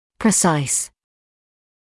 [prɪ’saɪs][при’сайс]точный, определённый